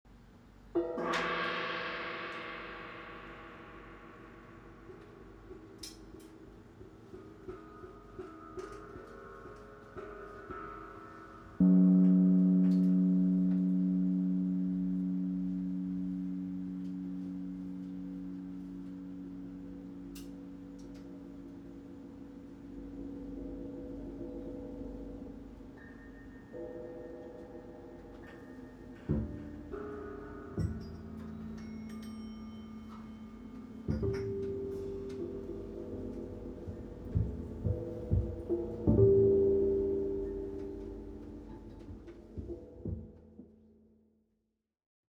Recorded on Oct. 9.2025 at Jazz Bar Nardis